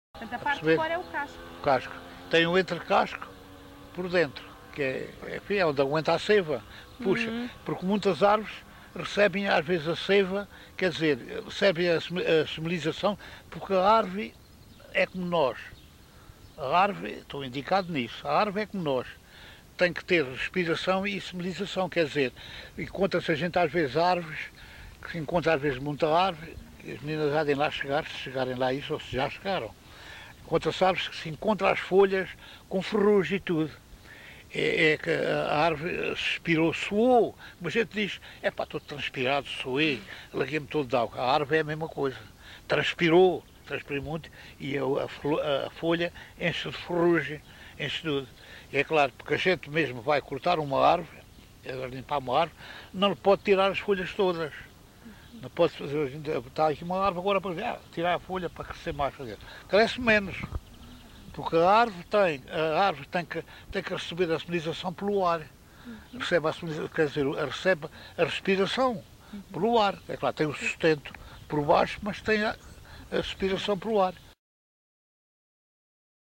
LocalidadeMontalvo (Constância, Santarém)